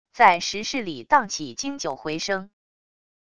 在石室里荡起经久回声wav音频